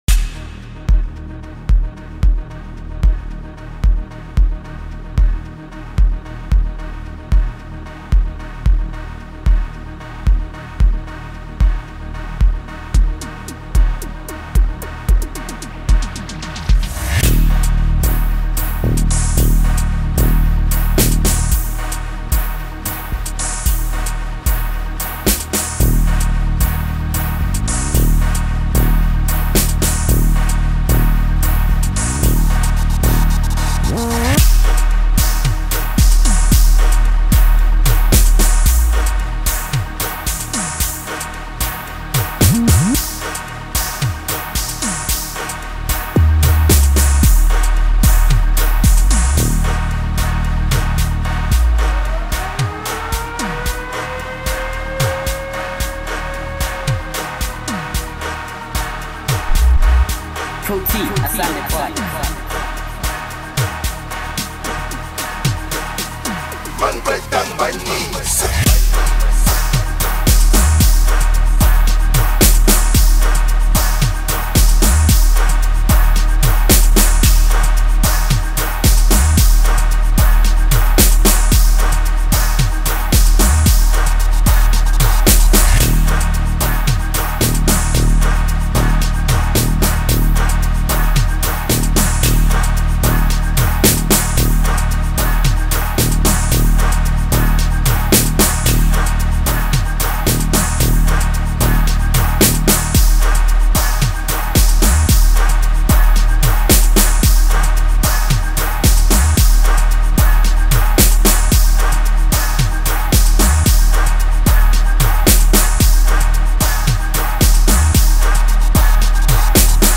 Home » Gqom